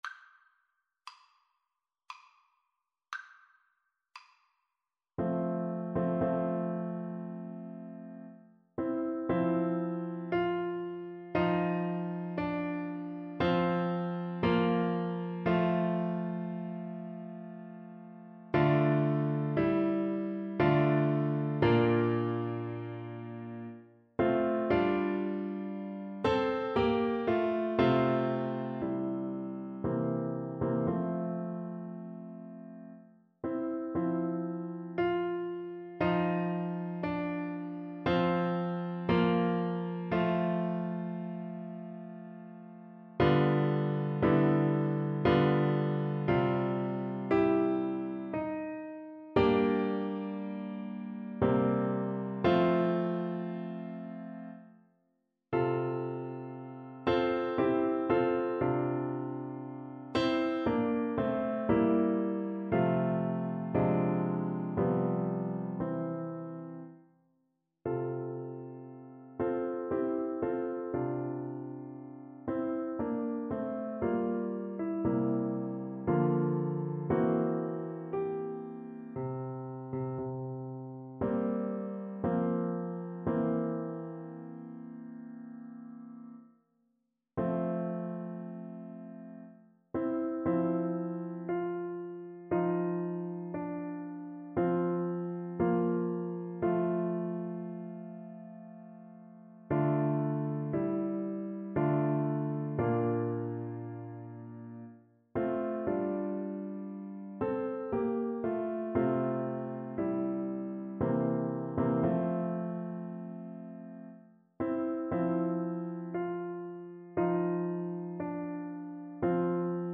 Play (or use space bar on your keyboard) Pause Music Playalong - Piano Accompaniment Playalong Band Accompaniment not yet available transpose reset tempo print settings full screen
ClarinetPiano
andante
3/4 (View more 3/4 Music)
F major (Sounding Pitch) G major (Clarinet in Bb) (View more F major Music for Clarinet )
Clarinet  (View more Easy Clarinet Music)
Classical (View more Classical Clarinet Music)